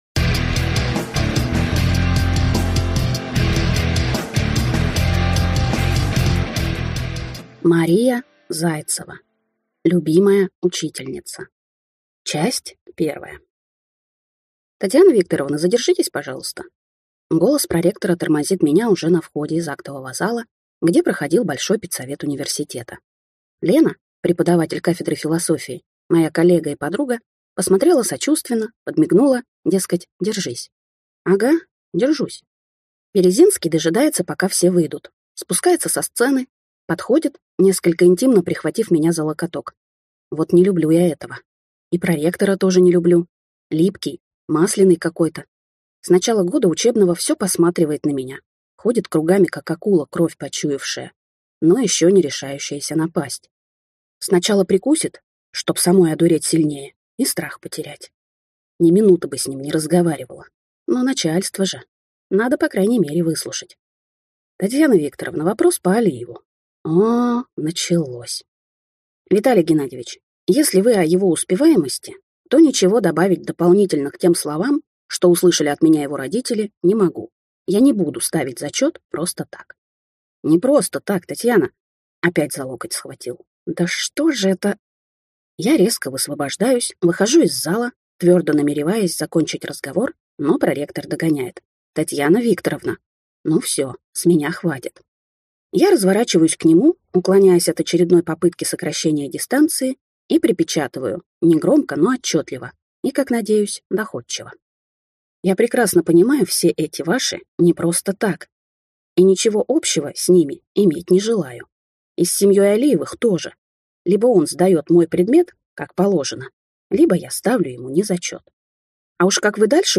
Аудиокнига Любимая учительница | Библиотека аудиокниг